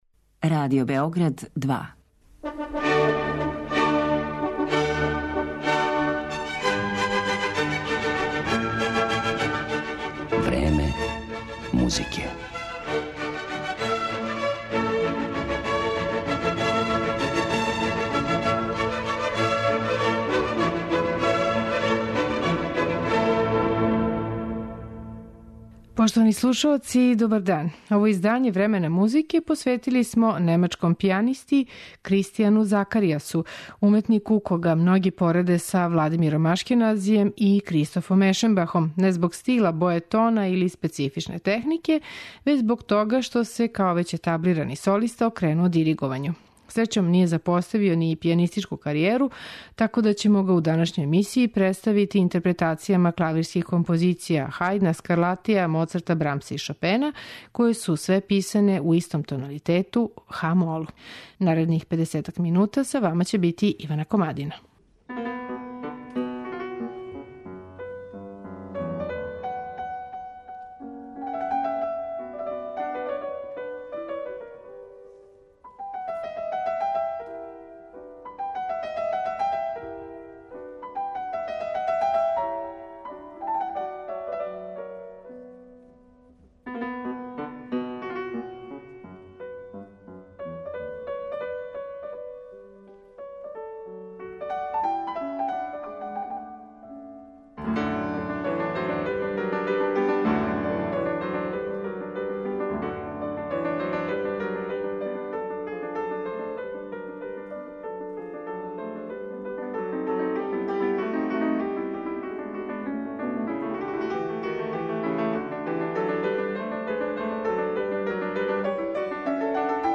клавирских композиција